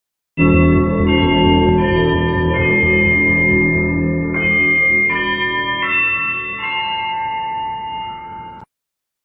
Squid Game Announcement Sound Button - Free Download & Play